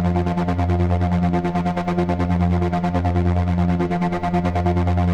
Index of /musicradar/dystopian-drone-samples/Tempo Loops/140bpm
DD_TempoDroneA_140-F.wav